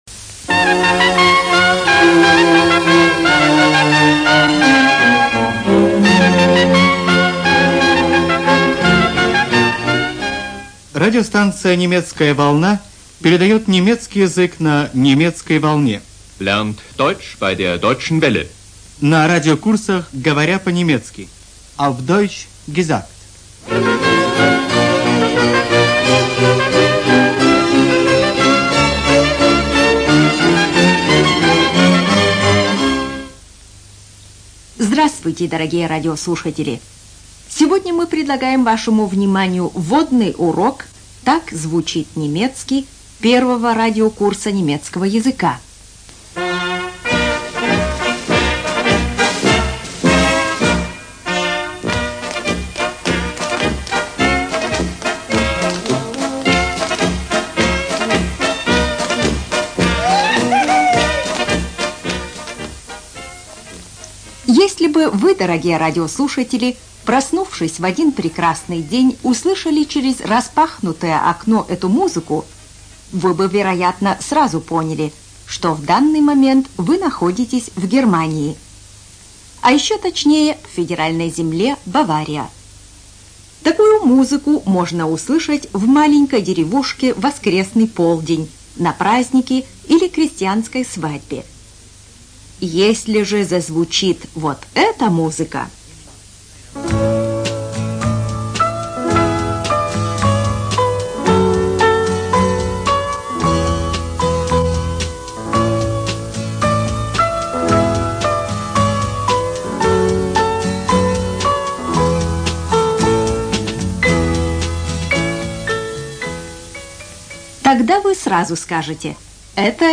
ЖанрИзучение иностранных языков, Радиопрограммы